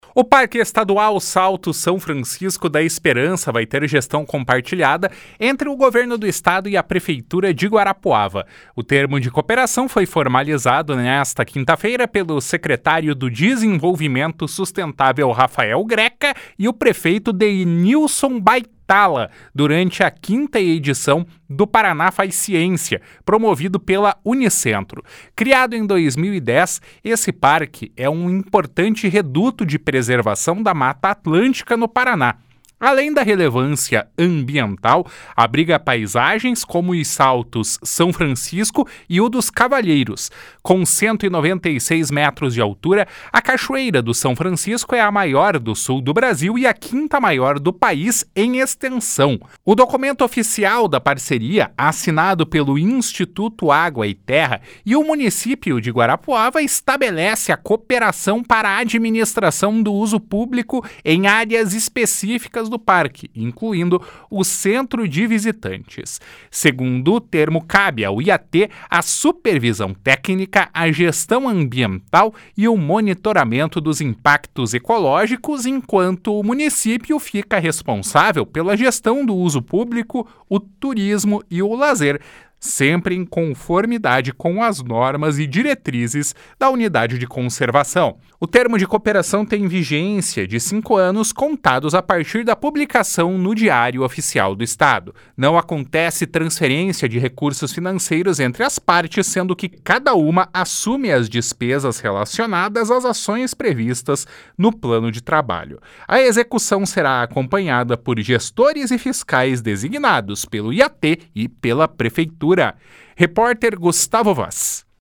O Parque Estadual Salto São Francisco da Esperança vai ter gestão compartilhada entre Governo do Estado e a Prefeitura de Guarapuava. O termo de cooperação foi formalizado nesta quinta-feira pelo secretário do Desenvolvimento Sustentável, Rafael Greca, e o prefeito Denilson Baitala, durante a quinta edição do Paraná Faz Ciência, promovido pela Unicentro.